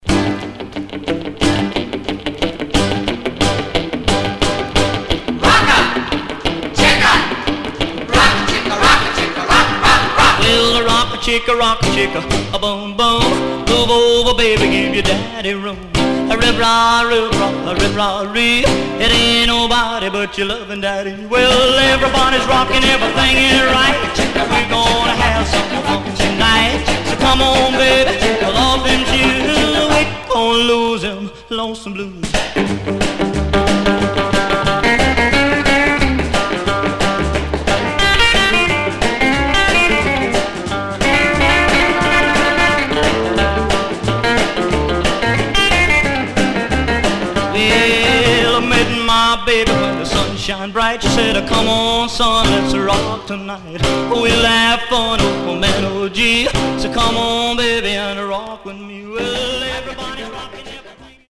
Mono
Rockabilly